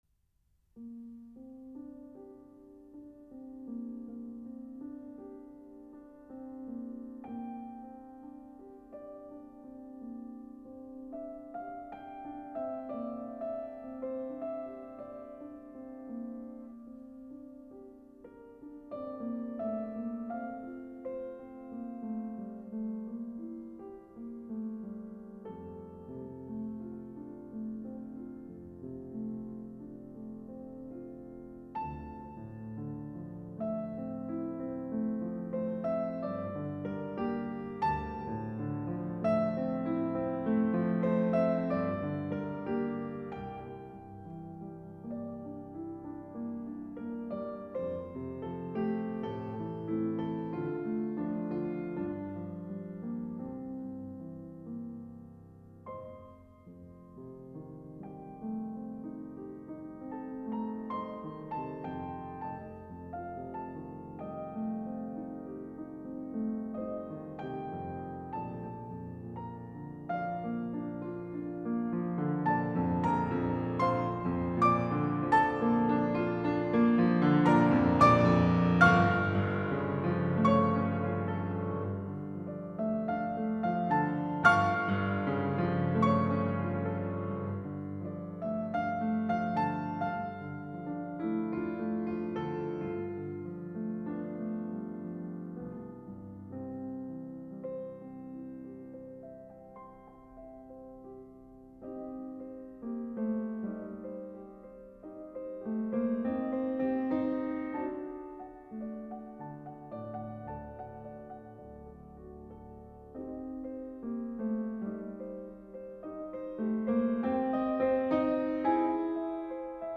Claude-Debussy-Reverie-Piano-Et-Harpe.mp3